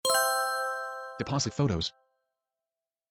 1. FX:
sound-magical-pickup-item.mp3